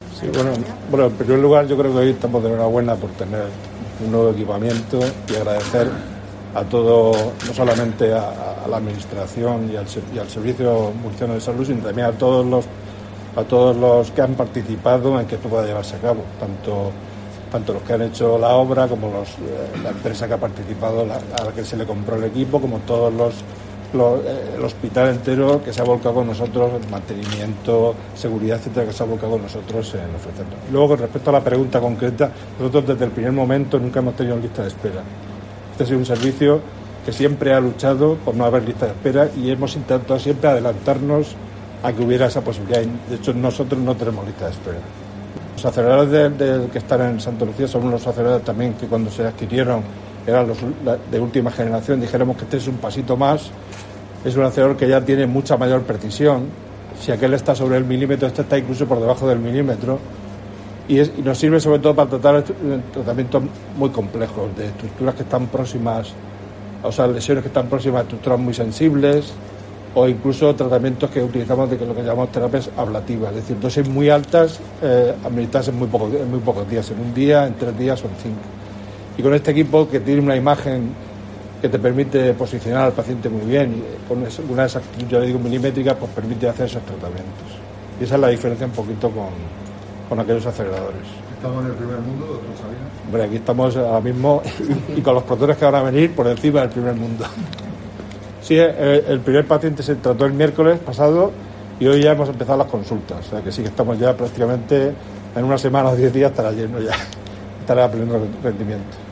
Audio: Declaraciones de la alcaldesa, Noelia Arroyo (MP3 - 1,13 MB)